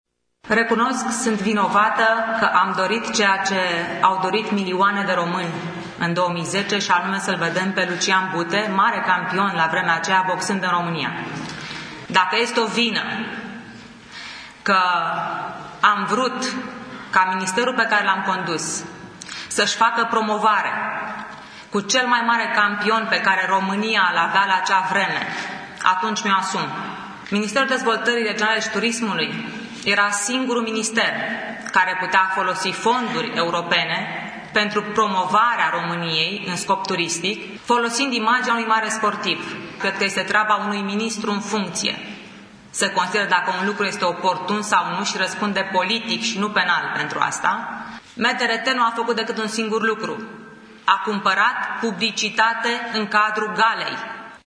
Aşa şi-a început Elena Udrea conferinţa de presă susţinută după ce DNA a cerut încuviintarea urmaririi penale împotriva sa în dosarul „Gala Bute”.